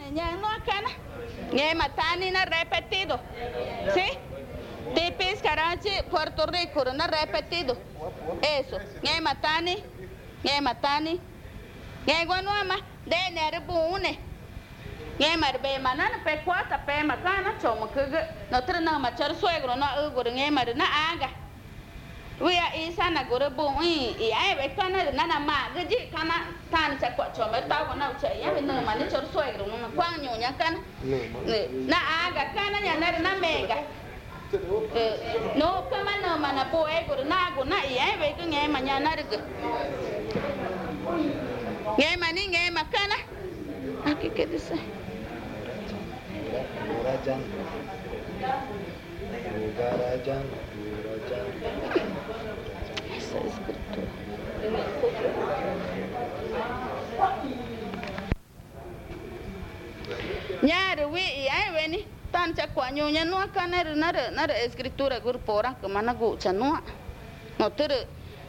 Tipisca, Amazonas (Colombia)